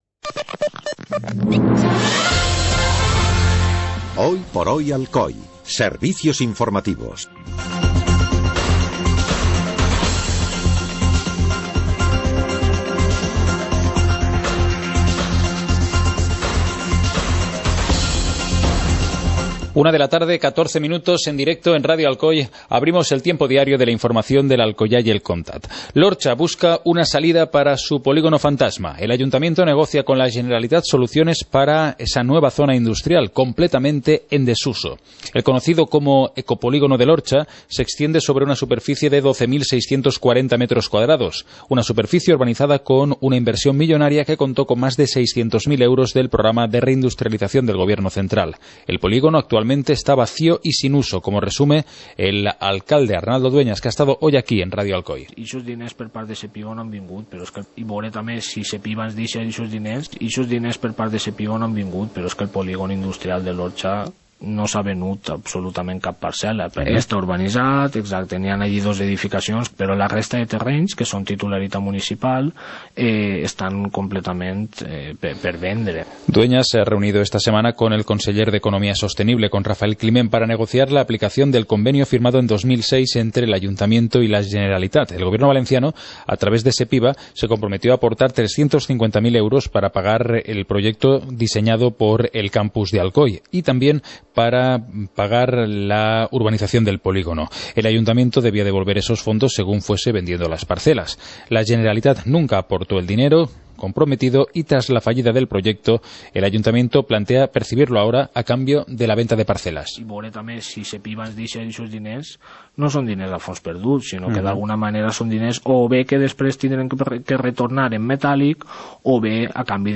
Informativo comarcal - miércoles, 04 de noviembre de 2015